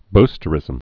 (bstə-rĭzəm)